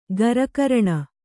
♪ garakaraṇa